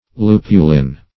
Search Result for " lupulin" : The Collaborative International Dictionary of English v.0.48: Lupulin \Lu"pu*lin\, n. [Cf. F. lupulin.
lupulin.mp3